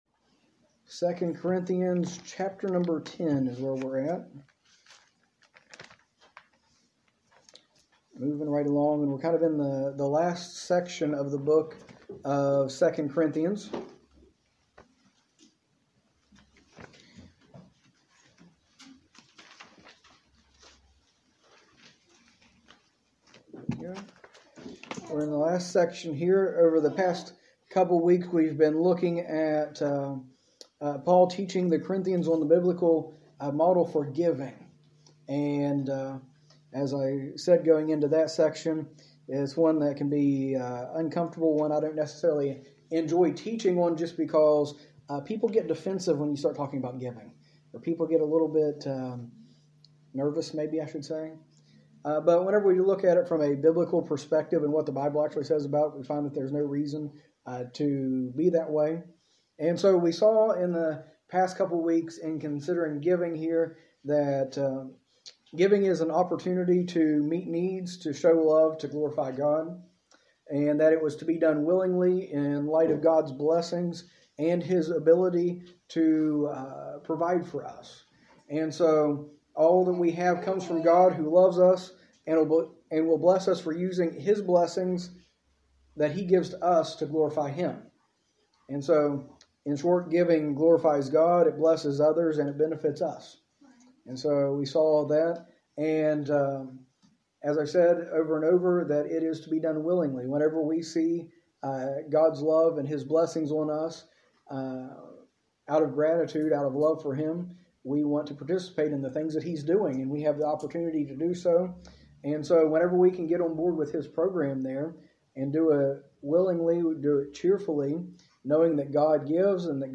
A message from the series "2 Corinthians."